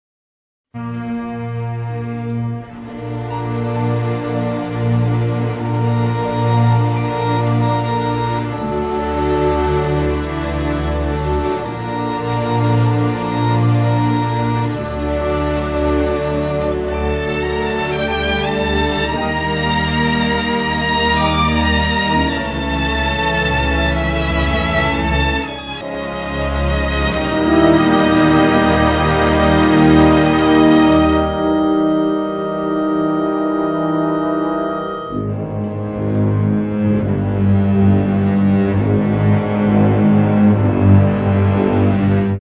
I had stuff played by real symphony orchestra in college, before DBZ...so yeah.
Before DBZ, live orchestra (sight read by students, no rehearsal)
SweetOrch.mp3